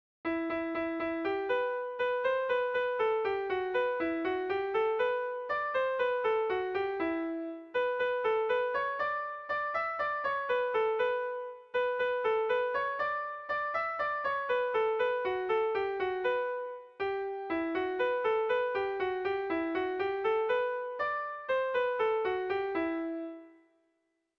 Air de bertsos - Voir fiche   Pour savoir plus sur cette section
Irrizkoa
ABDDEB